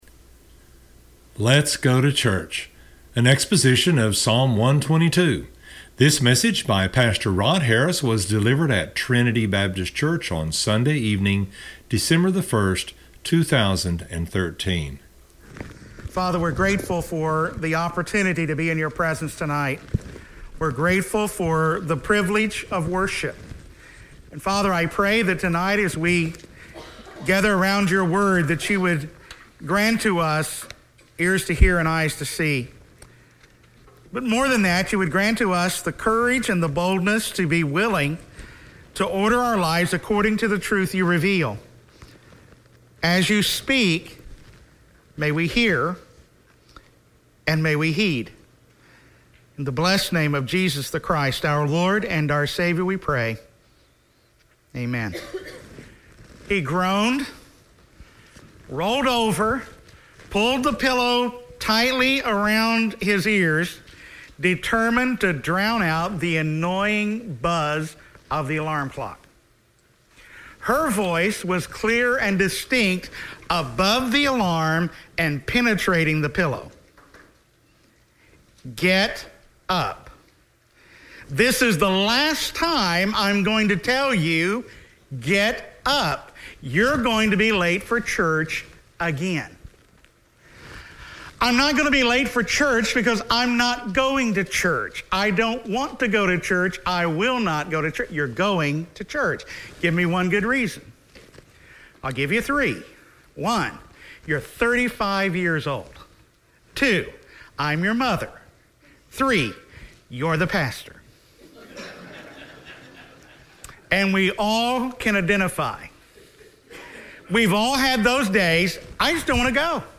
An exposition of Psalm 122.